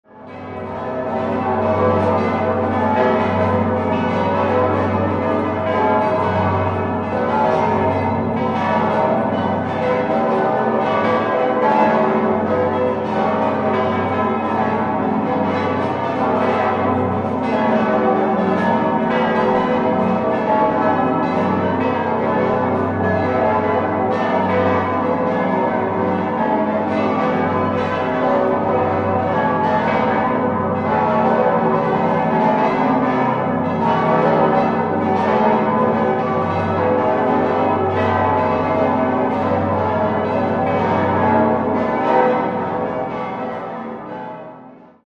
10-stimmiges Geläute: g°-a°-b°-c'-d'-e'-f'-g'-a'-c''
Ein mächtiges Geläute mit einer sehr ungewöhnlichen Disposition. Sie lässt jedoch eine Unzahl von Teilmotiven zu.
Straubing_Basilika.mp3